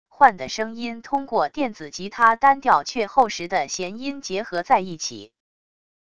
幻的声音通过电子吉他单调却厚实的弦音结合在一起wav音频